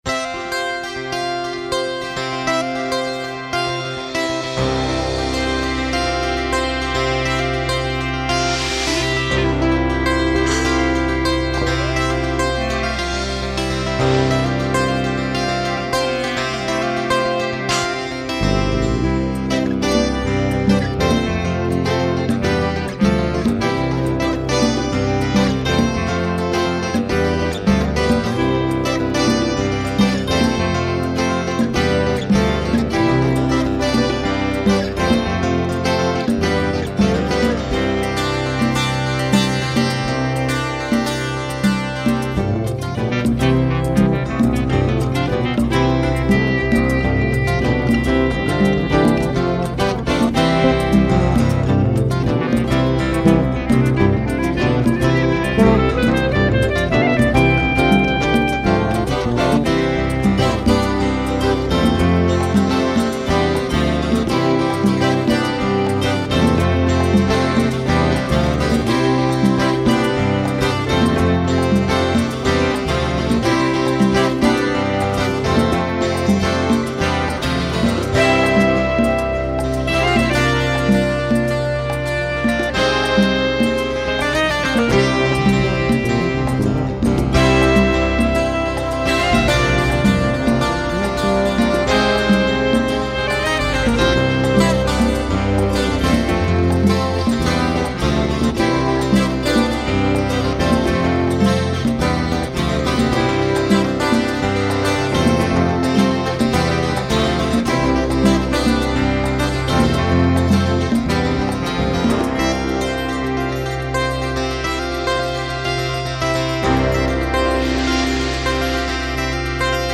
2913   05:52:00   Faixa: 1    Mpb